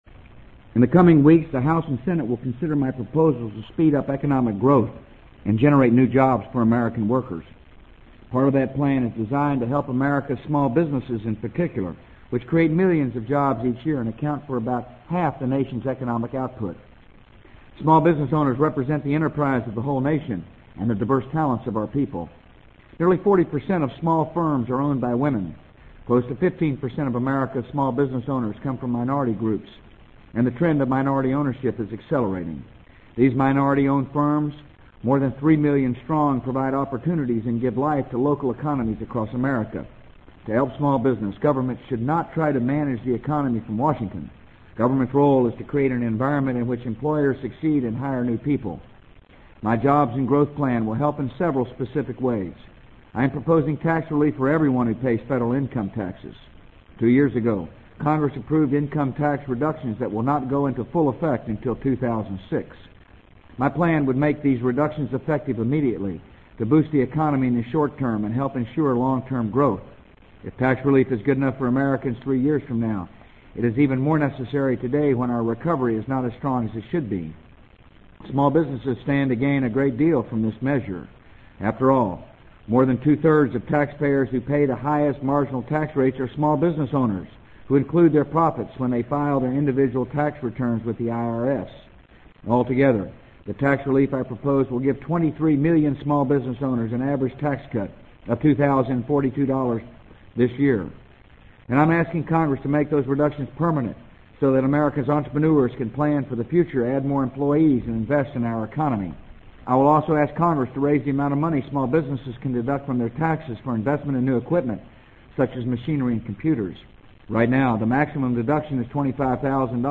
【美国总统George W. Bush电台演讲】2003-01-18 听力文件下载—在线英语听力室